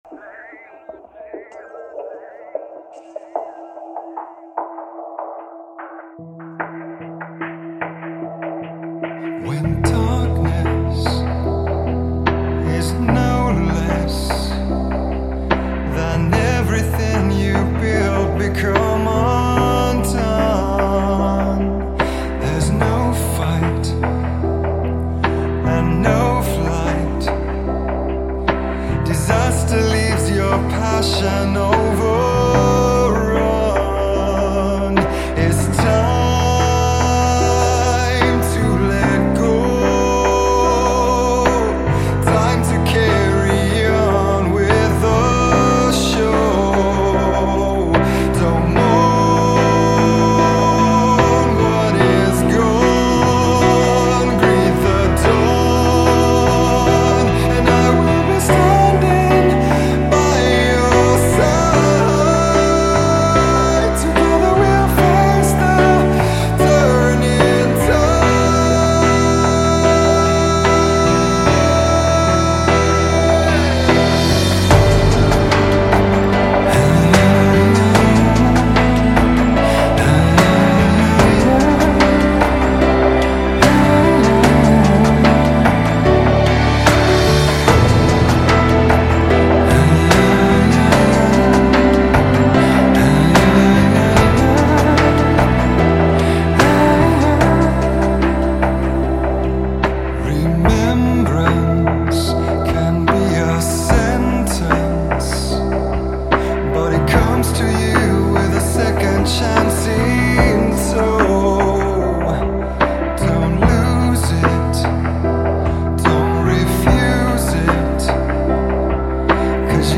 Genres: Pop Rock, Alternative Rock